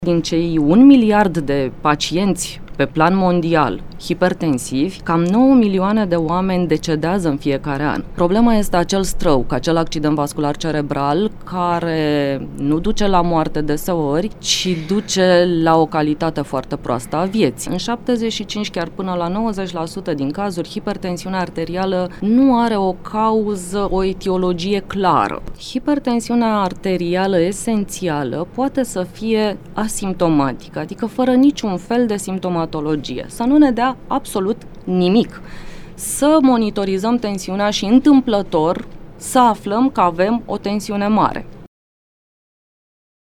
Despre incidenţa hipertensiunii arteriale a vorbit în Ediţia de dimineaţă